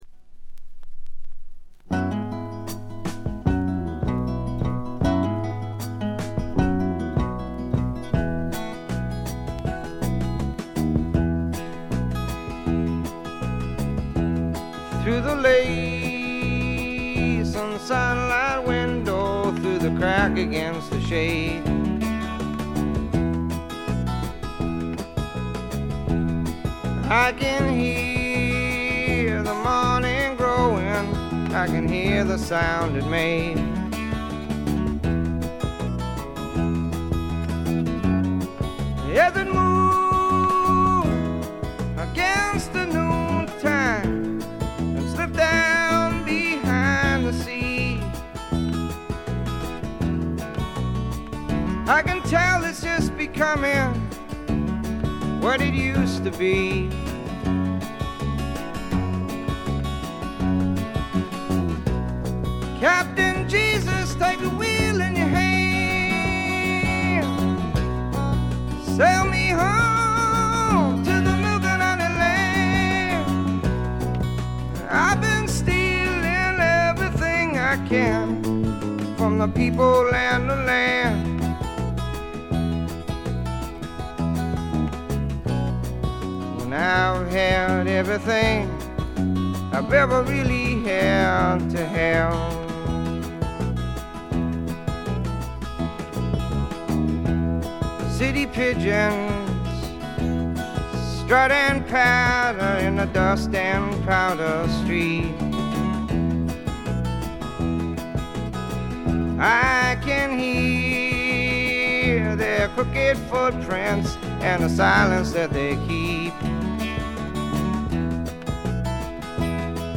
ごくわずかなチリプチ程度。
試聴曲は現品からの取り込み音源です。
Vocals, Guitar, Harmonica
Dobro, Guitar
Keyboards
Bass
Drums